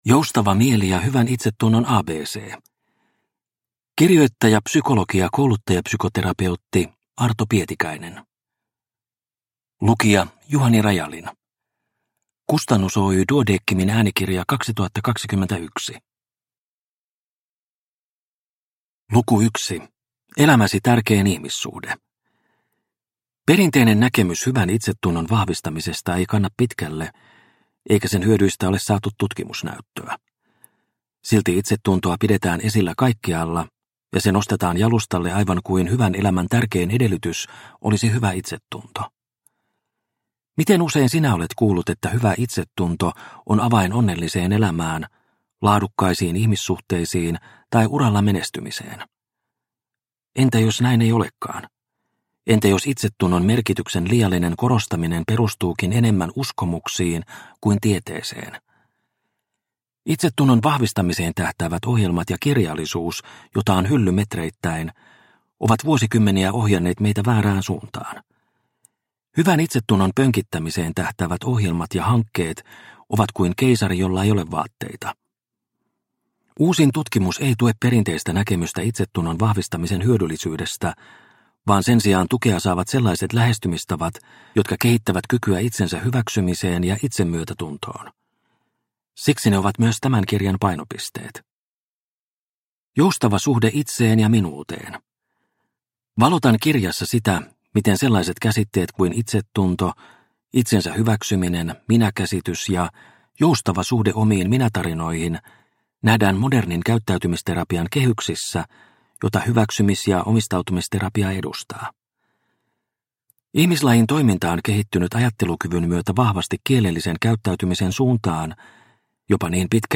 Joustava mieli ja hyvän itsetunnon ABC – Ljudbok – Laddas ner